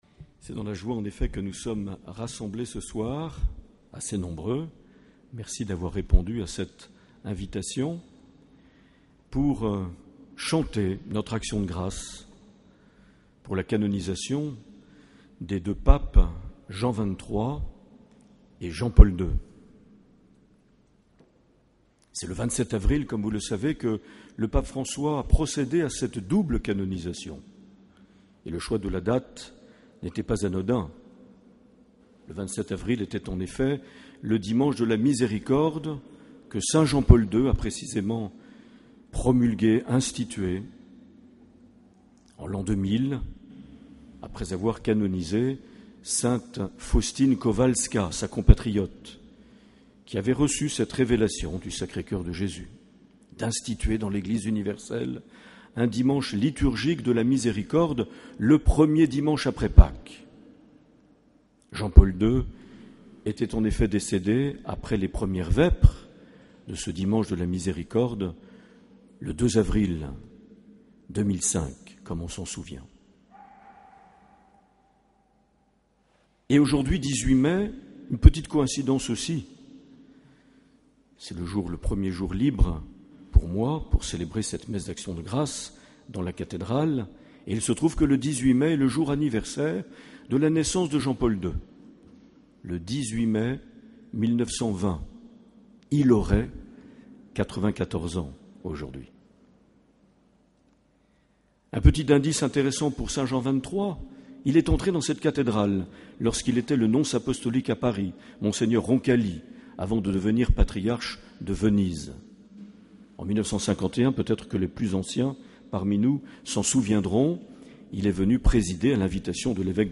18 mai 2014 - Cathédrale de Bayonne - Action de grâce canonisation Jean XXIII et Jean-Paul II
Accueil \ Emissions \ Vie de l’Eglise \ Evêque \ Les Homélies \ 18 mai 2014 - Cathédrale de Bayonne - Action de grâce canonisation Jean XXIII (...)
Une émission présentée par Monseigneur Marc Aillet